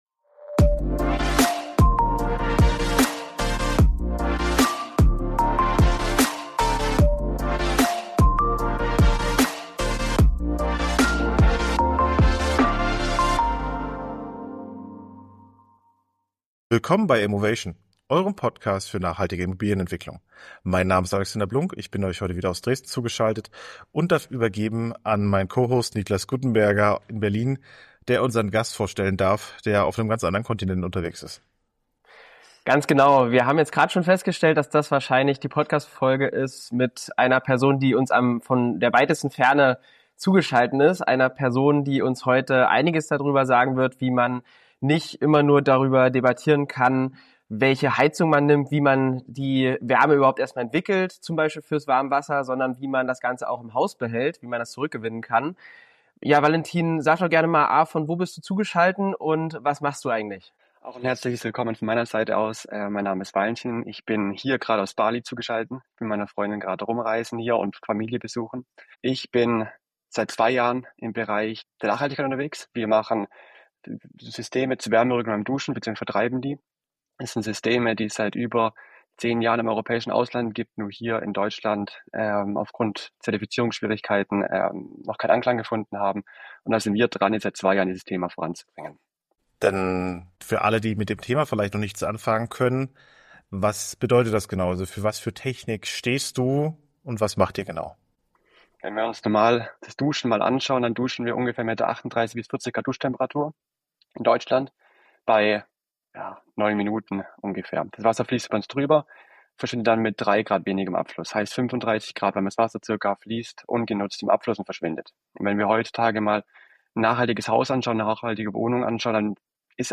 live aus Bali zugeschaltet